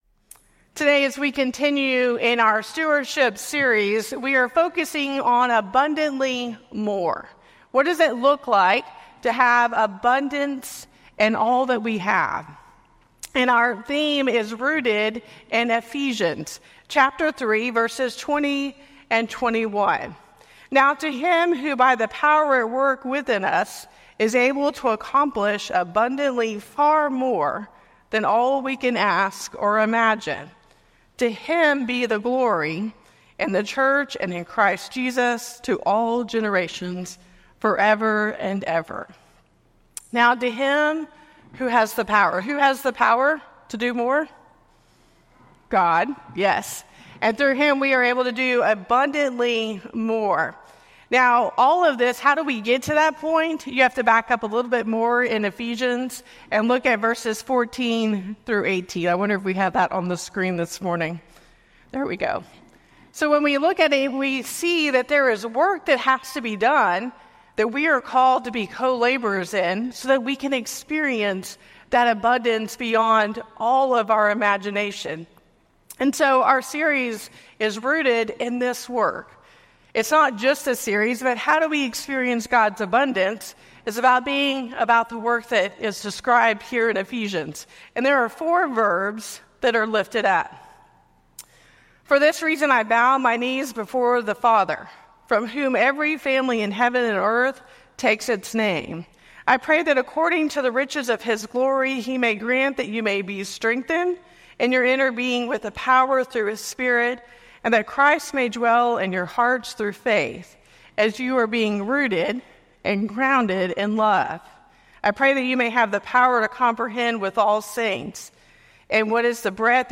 1 Corinthians 3:5-23 Service Type: Traditional https